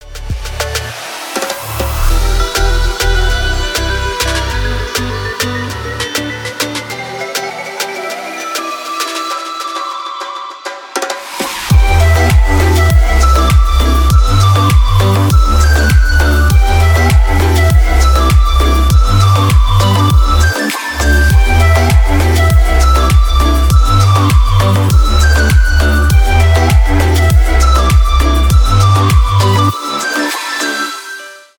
Instrumentalmusik, Alarm, Väckarklocka, Larm